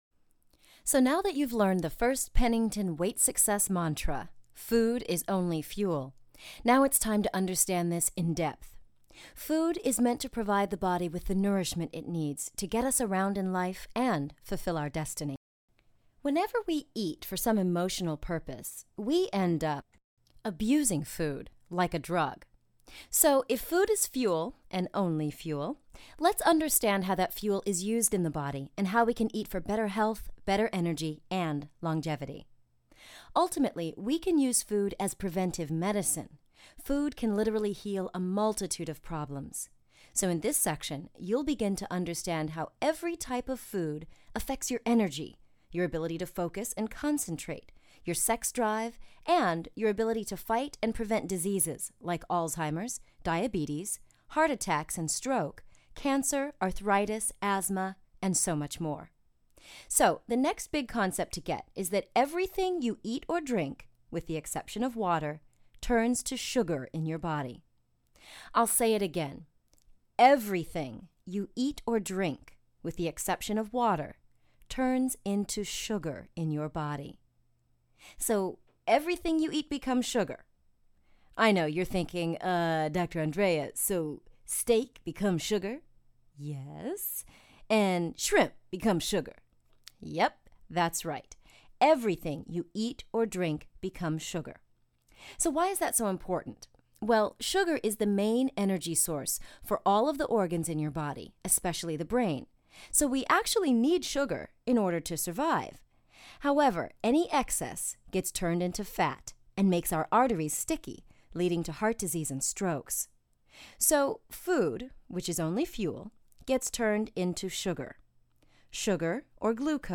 This is an audio excerpt from Eat to Live – Essential Secrets to Weight Loss & Ageless Vitality